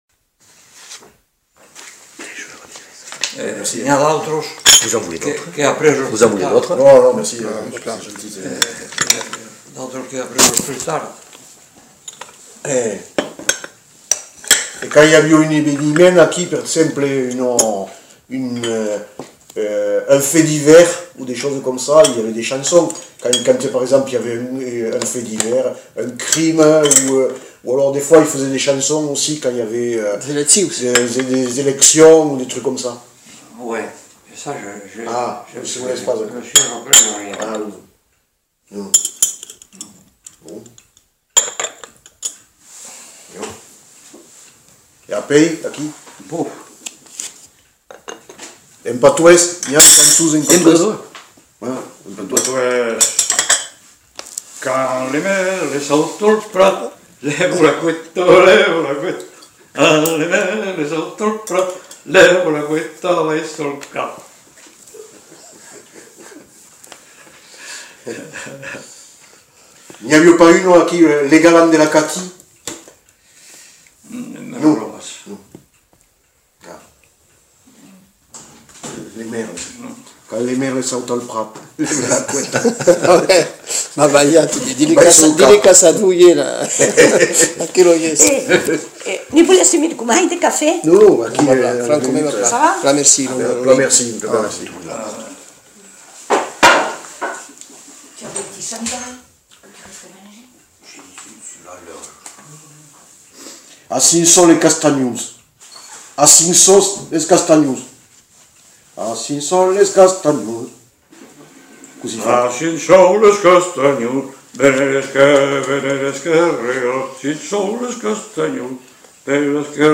Aire culturelle : Lauragais
Genre : chant
Effectif : 1
Type de voix : voix d'homme
Production du son : chanté
Danse : polka ; farandole ; varsovienne
Notes consultables : L'informateur évoque plusieurs chants en occitan, et en chante des bribes, en suivant son cahier de chant.